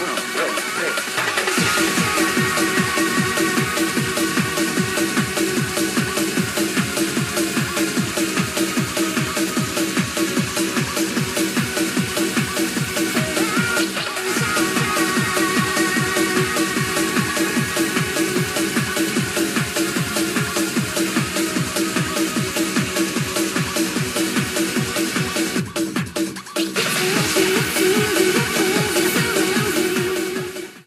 Música "Dance"